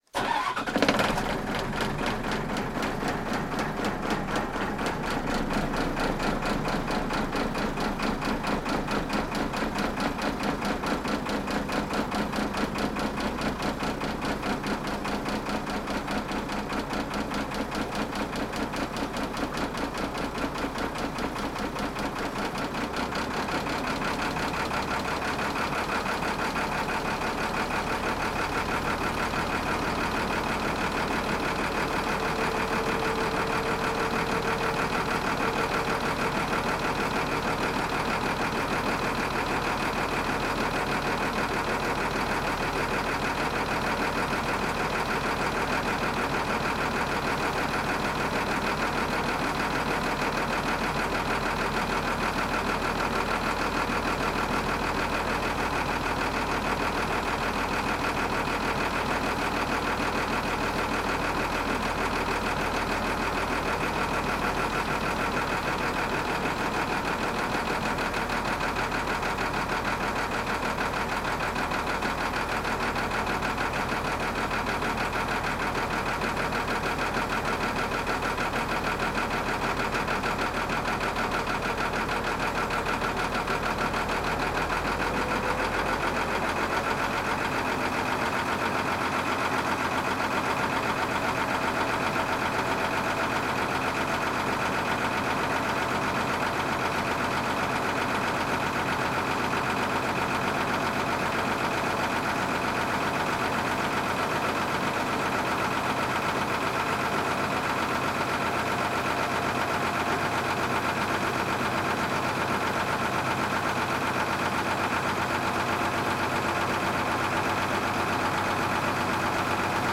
LANCHA LAGO TITICACA PRIMER PLANO DE MOTOR ENCENDIDO, NEUTRO Y ARRANQUE DESDE INTERIOR – Los sonidos del Perú
LANCHA-LAGO-TITICACA-PRIMER-PLANO-DE-MOTOR-ENCENDIDO-NEUTRO-Y-ARRANQUE-DESDE-INTERIOR.mp3